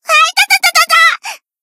BA_V_Koyuki_Battle_Damage_3.ogg